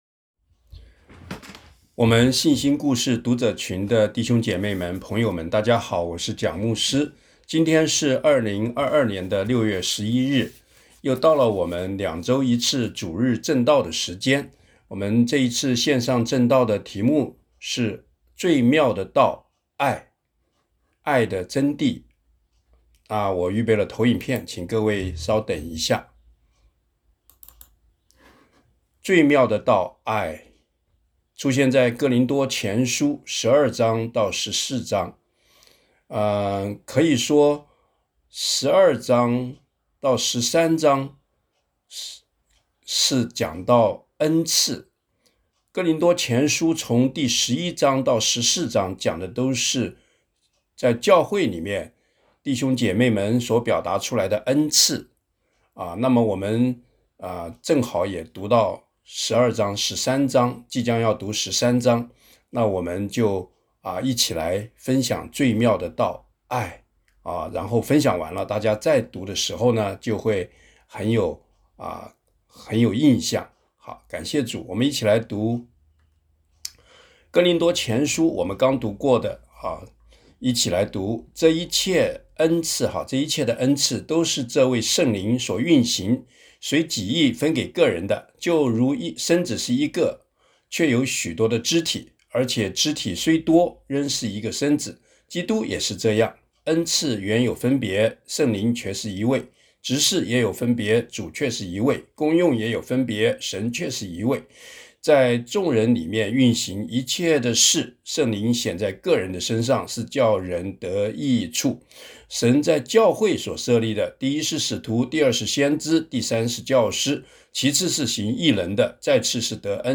《最妙的道，愛》证道 一、內容简介: ”爱“，是基督徒的核心价值，也是主耶稣吩咐门徒的大诫命，每个人都需要愛，也常在爱中挫折或受伤，在爱中有亏欠与无力感。